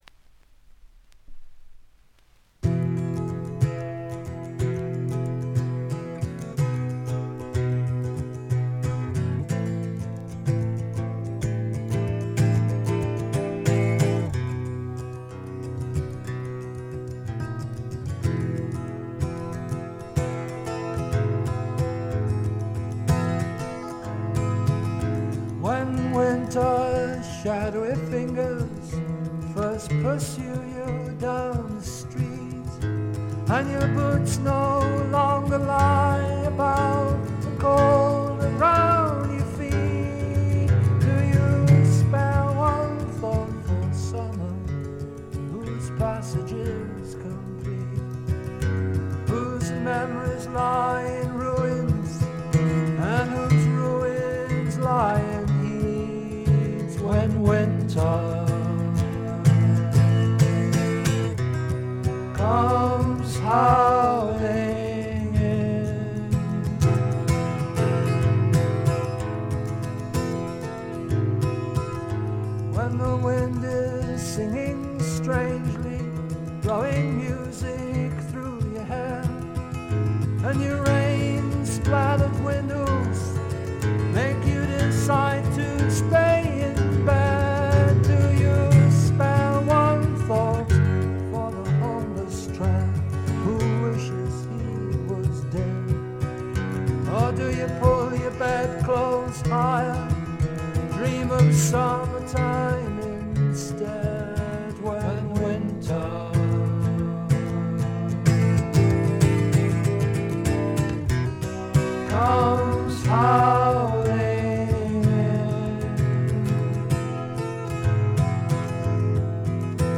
ところどころでチリプチ（特にB2は目立ちます）。鑑賞を妨げるようなノイズはありません。
試聴曲は現品からの取り込み音源です。